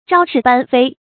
招是搬非 zhāo shì bān fēi
招是搬非发音
成语注音ㄓㄠ ㄕㄧˋ ㄅㄢ ㄈㄟ